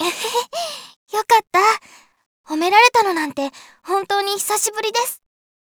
(声：川澄綾子)
samplevoice